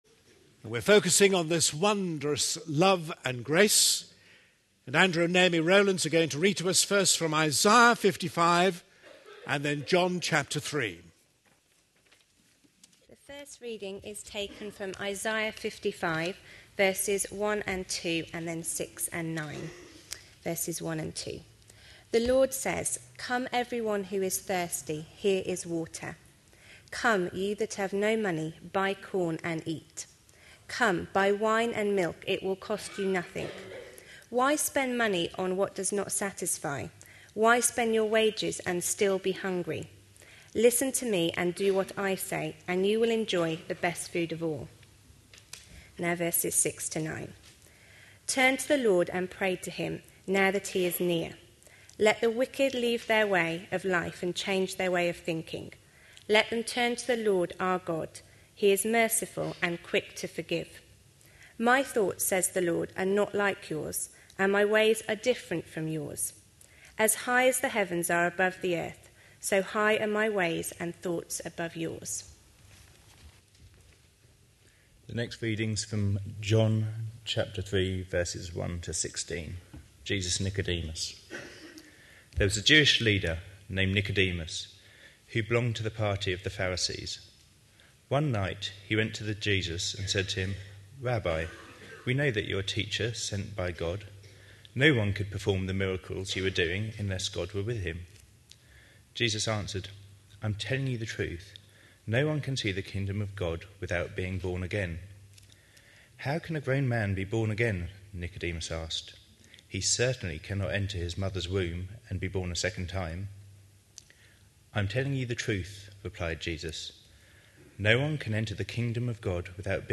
A sermon preached on 29th January, 2012, as part of our Looking For Love (10am Series) series.